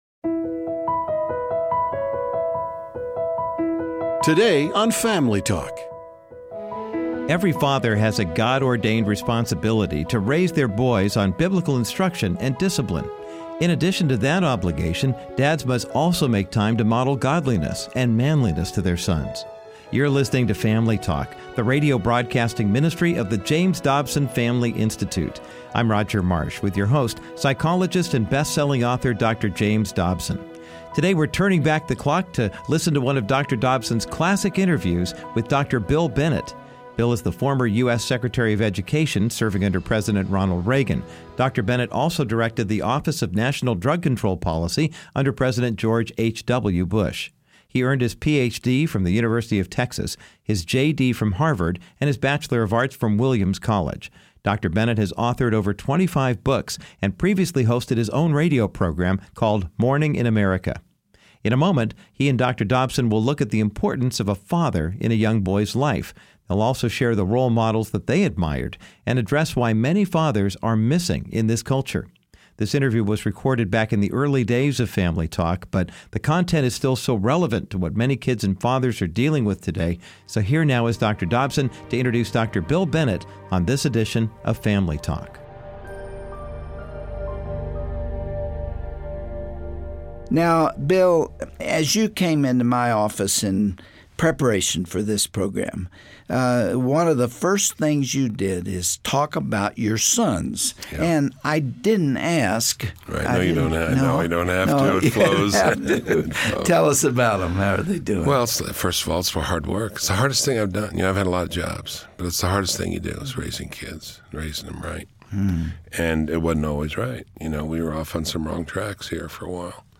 On this classic Family Talk broadcast, Dr. Dobson sits down with Dr. Bill Bennett, former U.S. Education Secretary under President Reagan. They look at the need for a male influence in a sons life, and identify the various male role models they looked up to over the years.